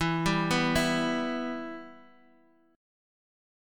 Em chord